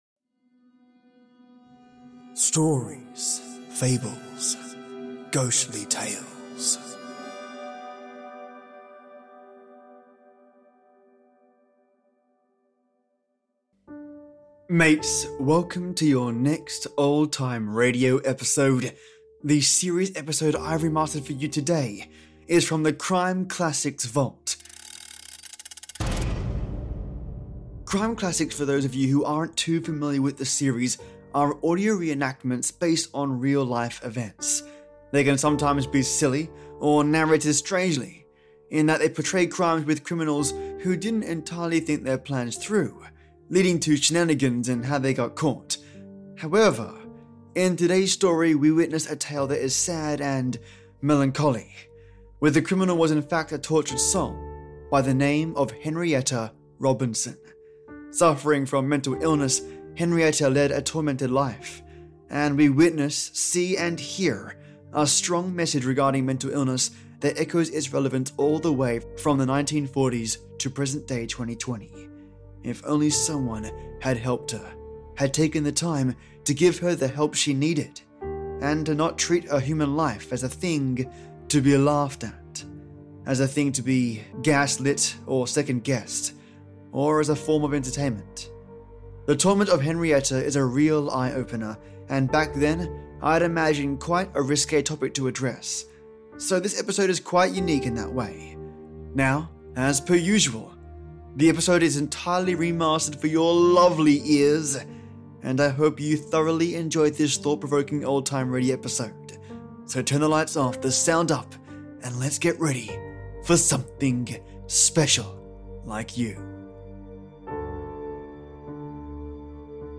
The series episode I’ve remastered for you, is from the Crime Classics vault. Crime Classics for those of you who aren’t too familiar with this series are audio re-enactments based on real life events.